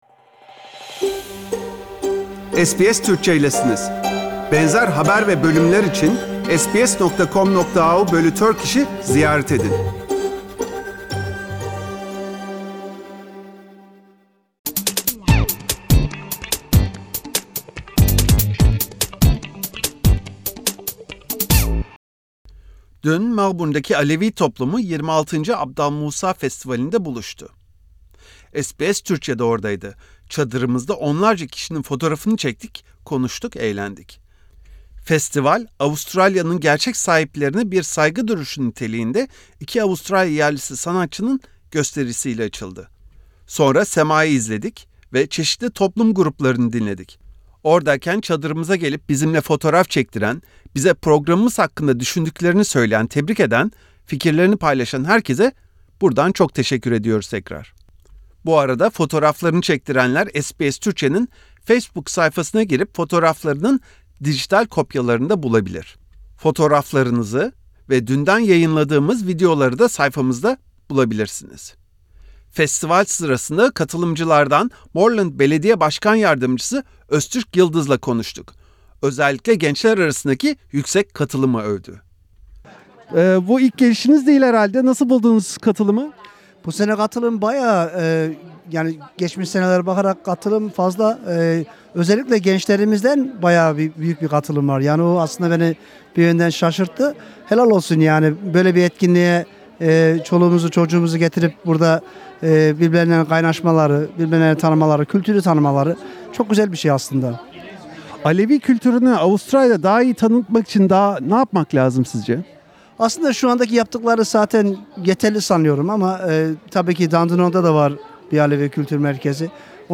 Festival hakkında Moreland Belediye Başkan Yardımcısı Öztürk Yılmaz